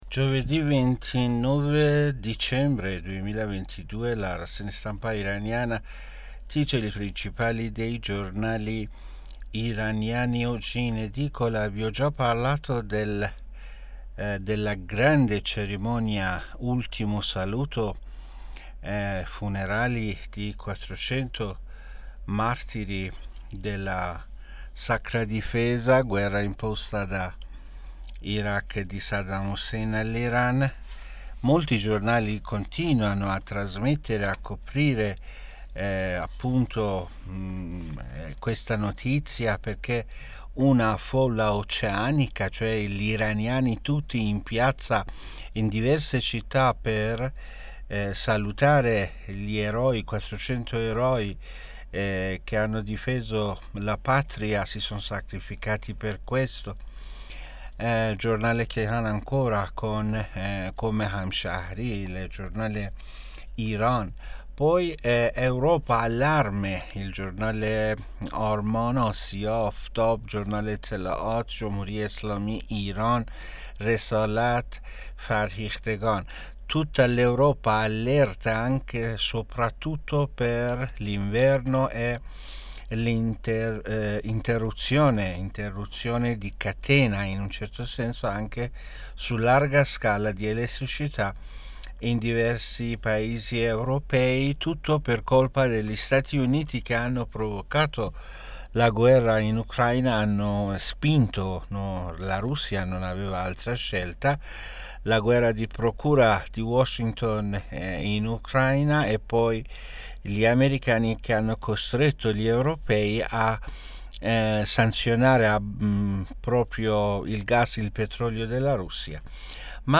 Rassegna Stampa Iran Giovedi' 29 Dicembre 2022 (AUDIO)